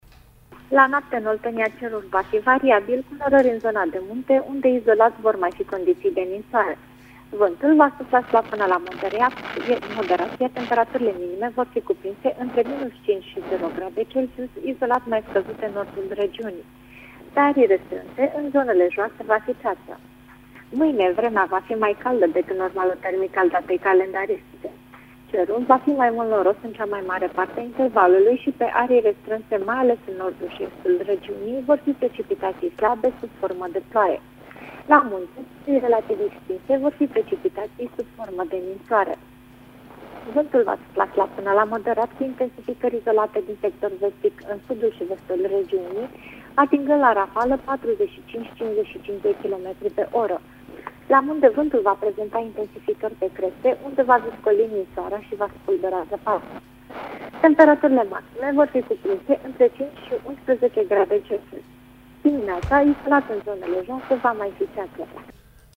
Meteo
Prognoza meteo 27/28 decembrie (audio)